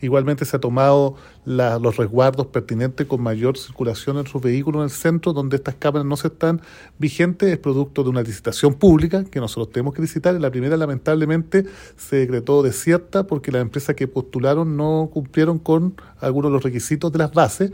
Por su parte, el alcalde de Temuco, Roberto Neira, explicó que la primera licitación se declaró desierta porque las empresas que postularon no cumplían con las exigencias de las bases.
cuna-camaras-3-alcalde.mp3